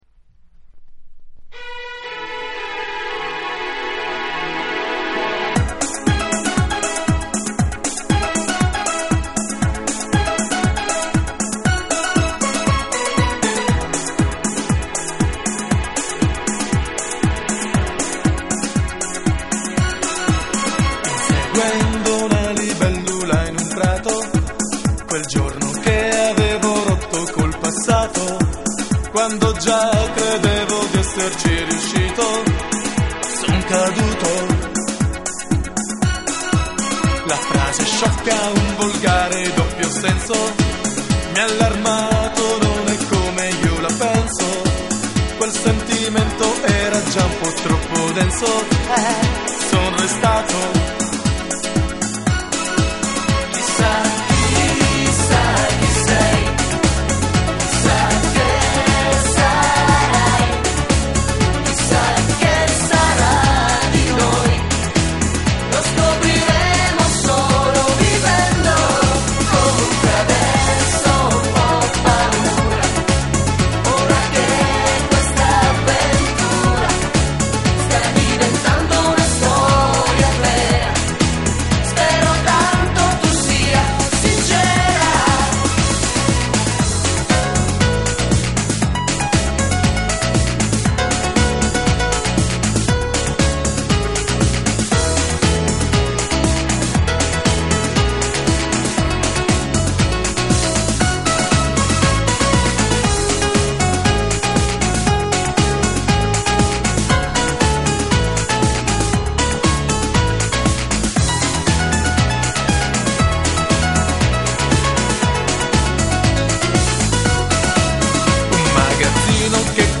Recording and mixed: May Day Studio (Milan)
Rifacimento del noto brano